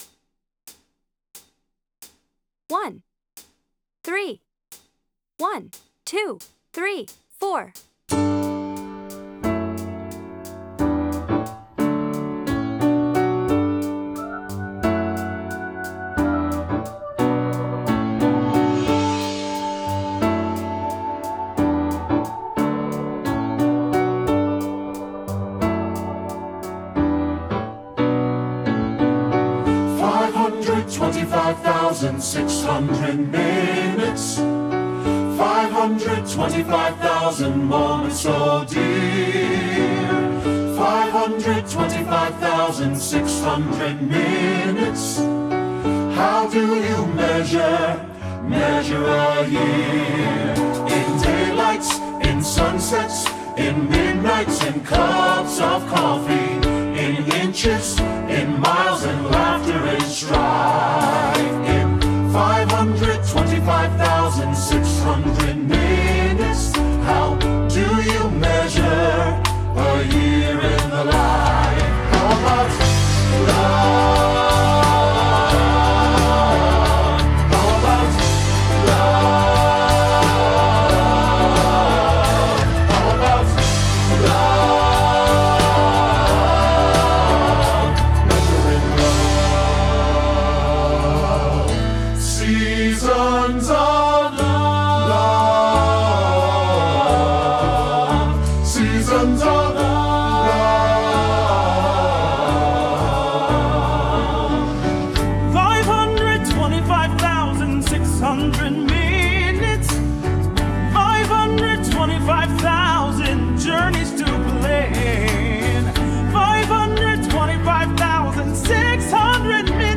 in the key of D